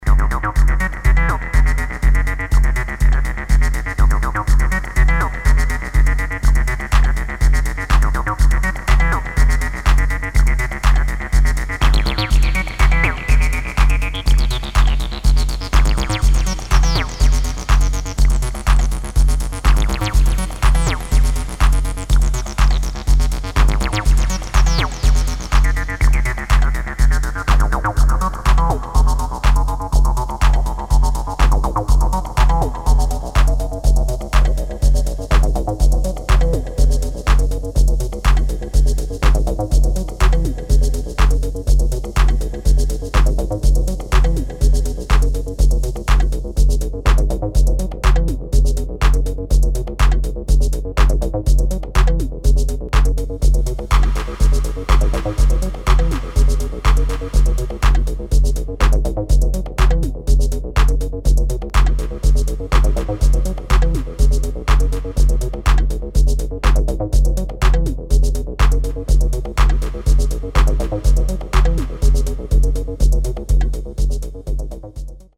[ TECHNO / MINIMAL / ACID / HOUSE ]